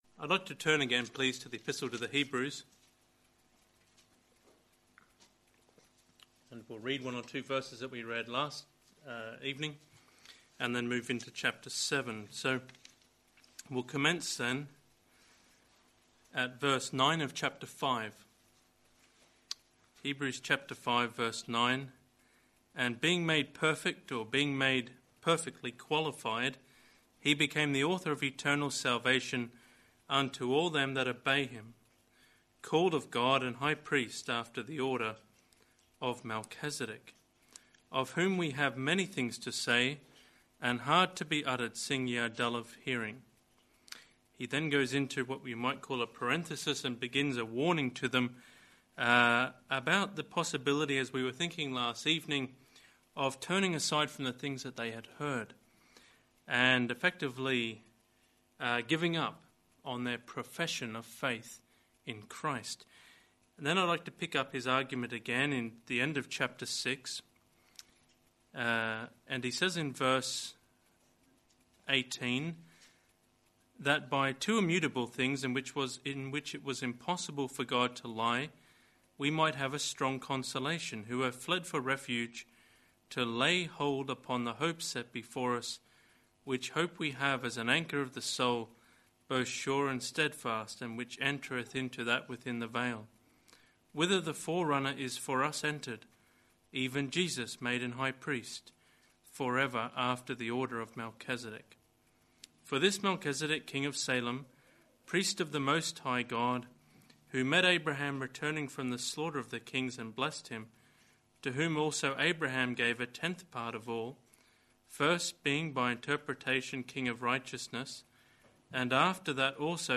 This message was preached at Osborne Road Gospel Hall, Northampton.